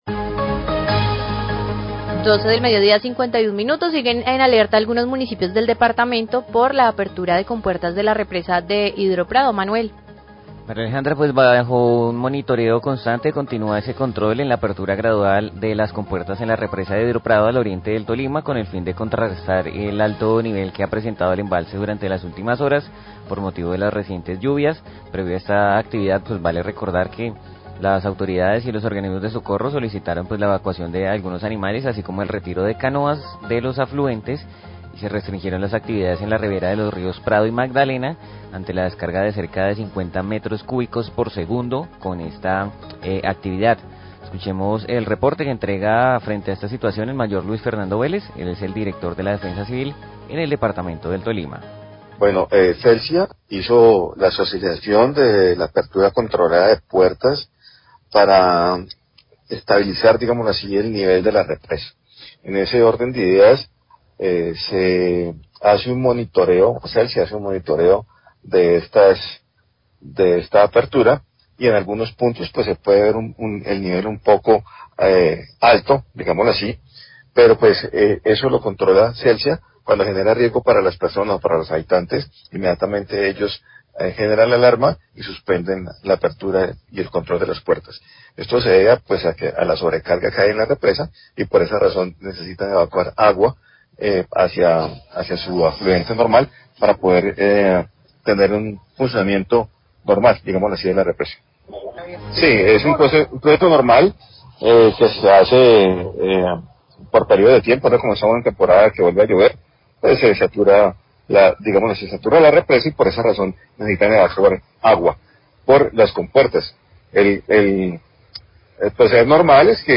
Radio
El director de la Defensa Civil del Tolima, My Luis Fernando Vélez,  presenta un reporte frente a la situación de la apertura gradual de las compuertas de la represa de Prado. Pide calma a las comunidades ribereñas aguas abajo de los municipios del Prado y Purificación.